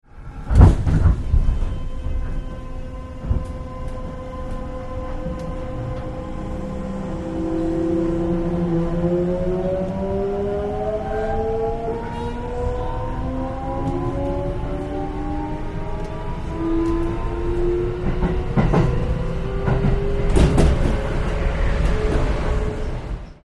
2.　変調音
こちら（ギア比6.31）：VX01 / VX04